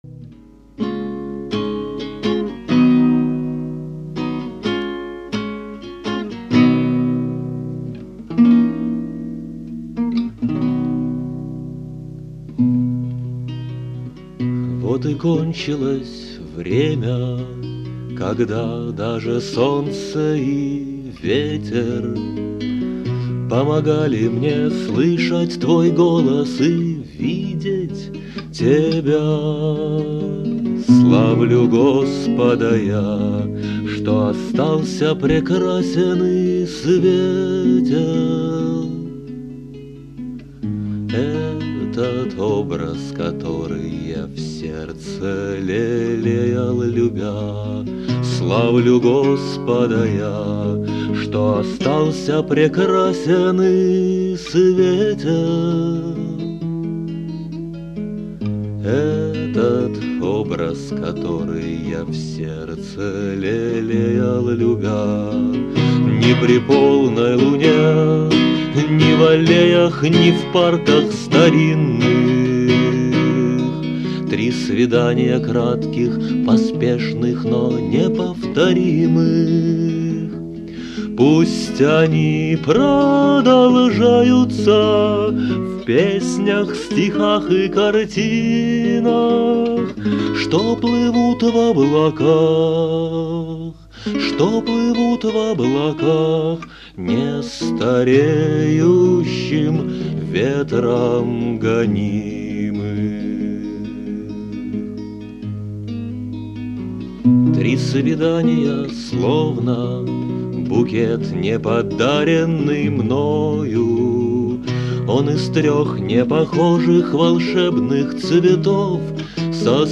песни 1992-97 гг. в исполнении автора.